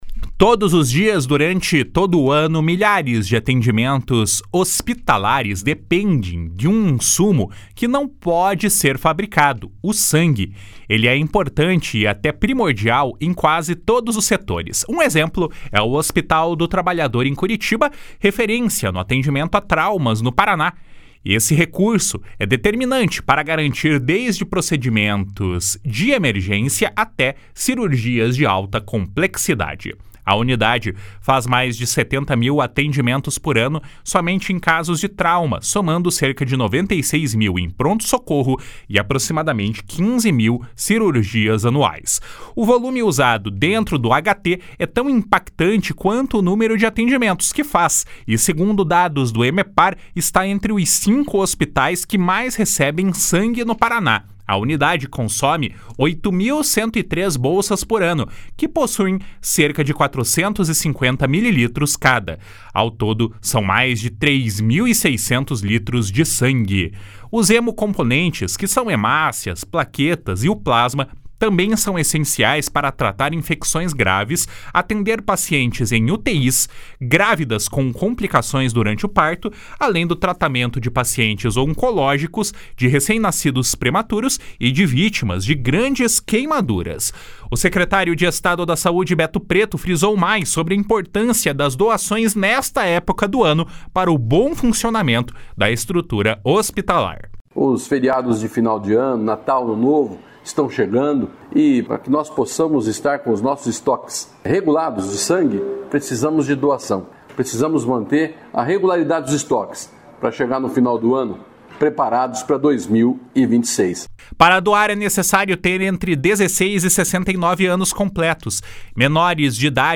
O secretário de Estado da Saúde, Beto Preto, frisou mais sobre a importância das doações nesta época do ano para o bom funcionamento da estrutura hospitalar. // SONORA BETO PRETO //